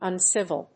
音節un・civ・il 発音記号・読み方
/`ʌnsív(ə)l(米国英語)/